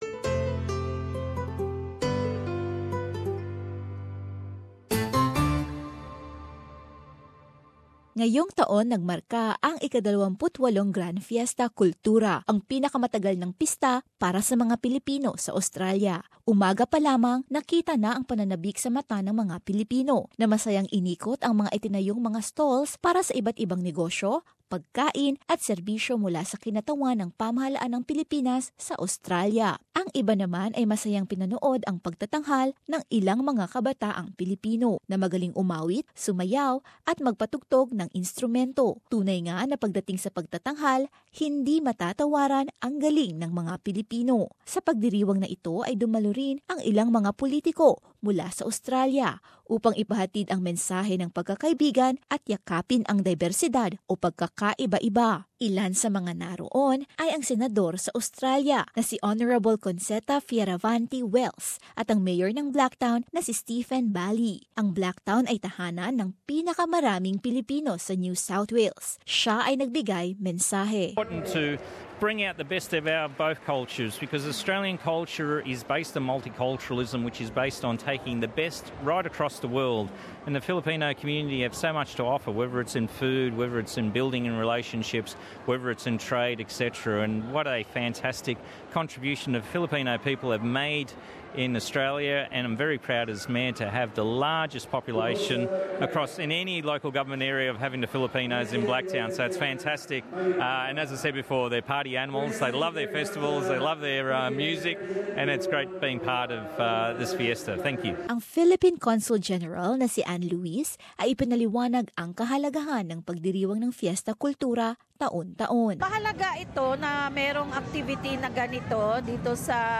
SBS Filipino